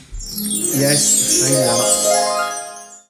Play, download and share Fade up music original sound button!!!!
fade-up-music.mp3